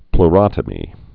(pl-rŏtə-mē)